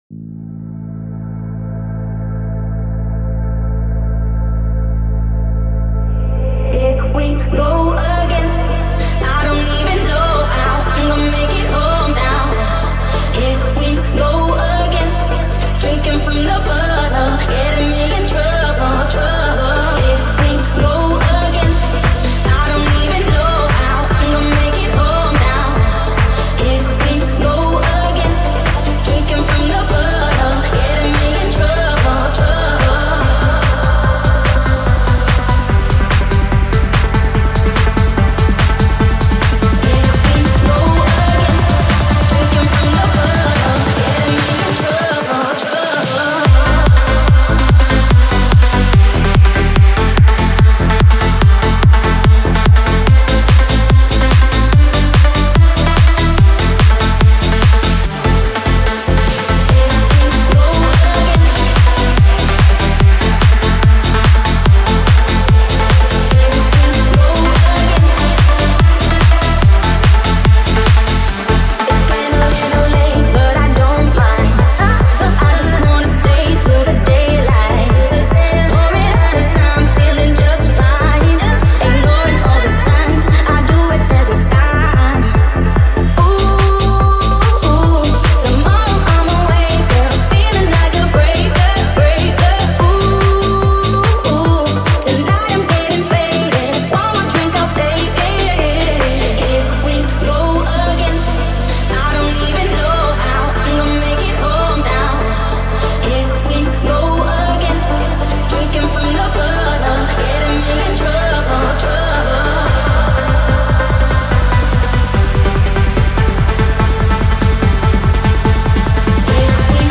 • Жанр: EDM, Dance